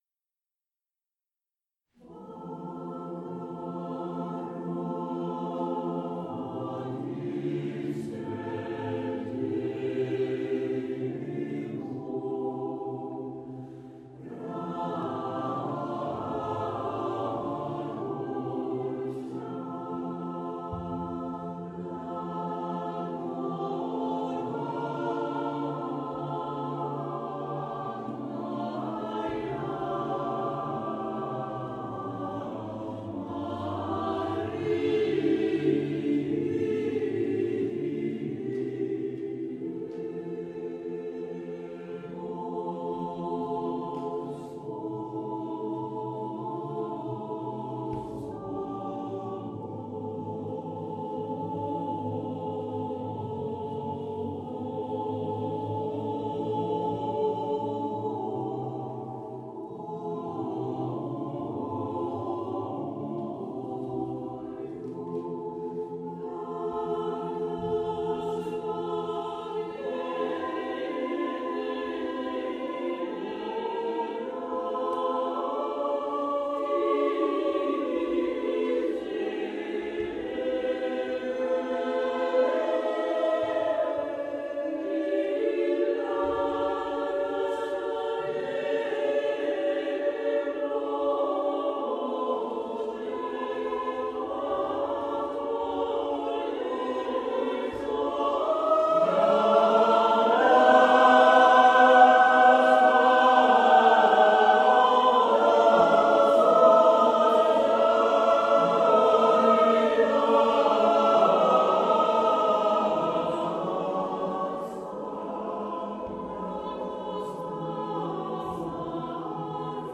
LYSSNA | Bålsta kammarkör
Bogoroditse Devo av S Rachmaninoff, från konsert ”Höstfärger” 29 okt 2016 i Övergrans kyrka
Bogoroditse-Devo-Cathedral-.mp3